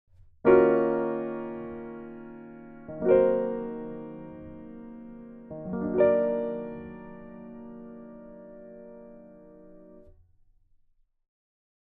1. Minor 7, Minor 6, and Minor(maj7) chords
They immediately create that uneasy, mysterious, slightly brooding feeling.
minor-noir-chords.mp3